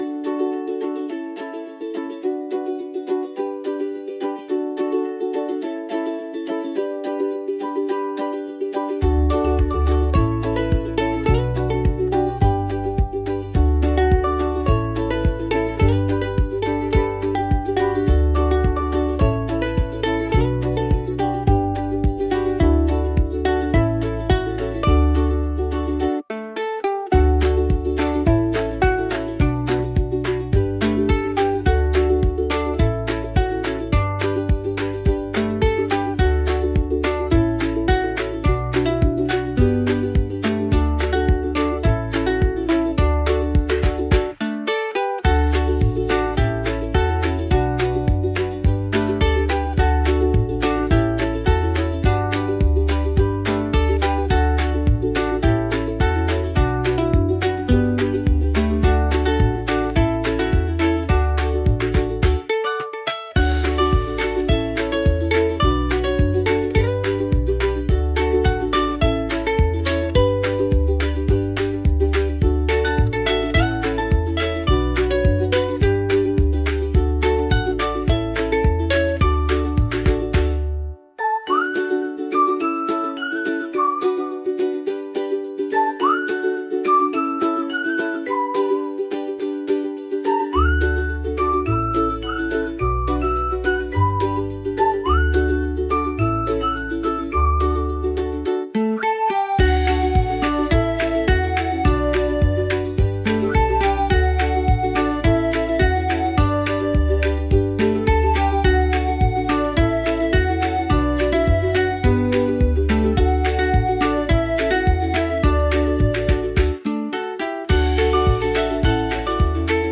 フリーBGM 🎶 明るくて親しみやすいポップな音色が、日常を軽やかに彩るBGMです。